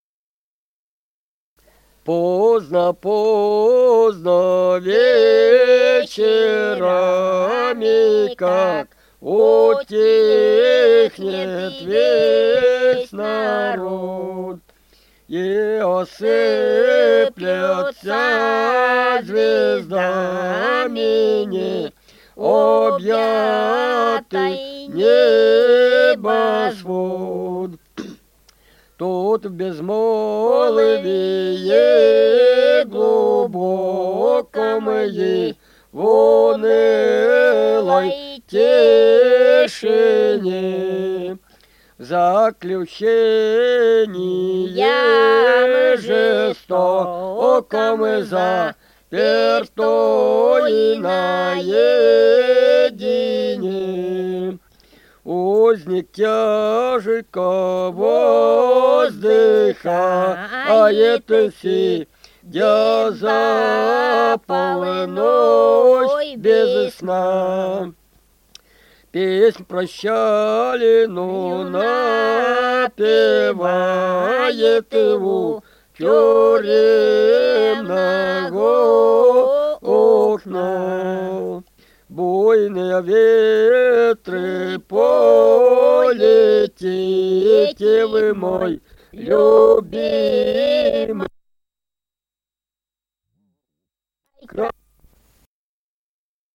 Русские песни Алтайского Беловодья 2 «Поздно, поздно вечерами», стих узника-невольника.
Республика Алтай, Усть-Коксинский район, с. Тихонькая, июнь 1980.